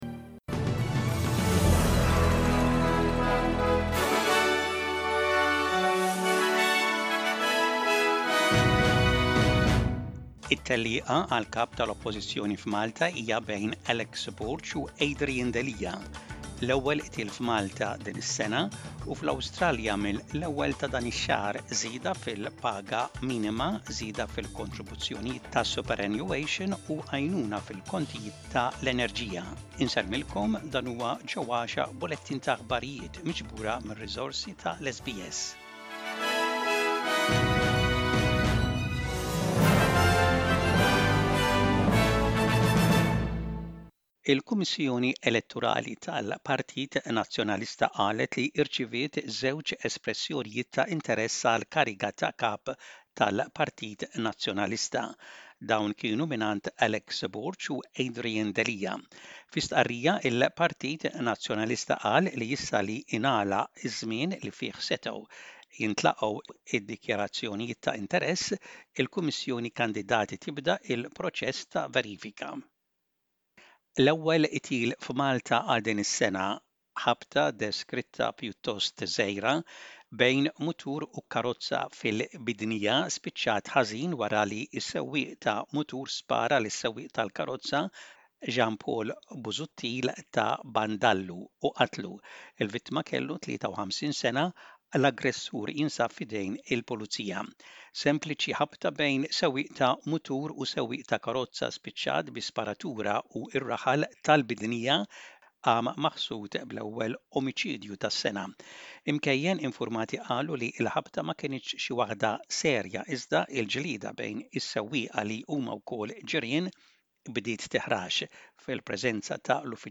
Aħbarijiet bil-Malti: 01.07.25